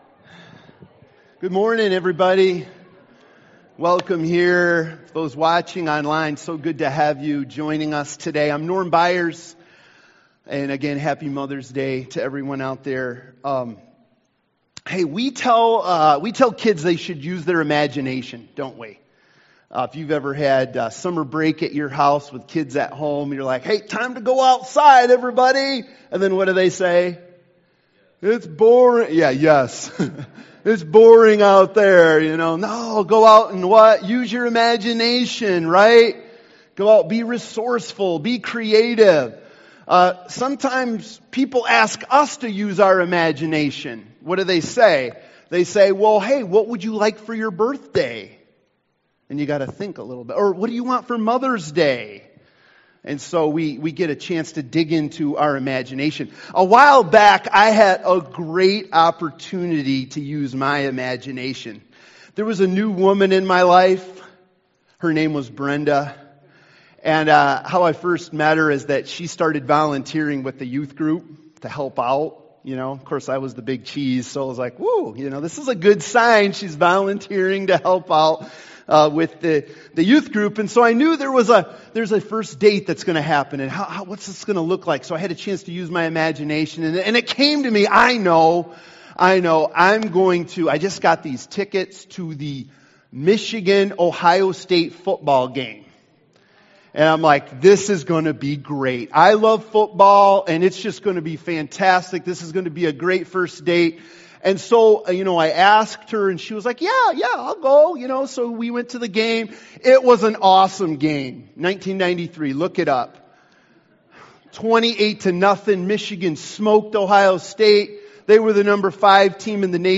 Learning to Be Discerning Service Type: Sunday Morning « Embracing The Goodness of God What Path Am I On?